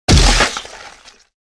Вы найдете разные варианты ударов – резкие, глухие, с эхом – для использования в монтаже, играх или других творческих задачах.
Звук разрыва головы от выстрела в упор башка на мелкие куски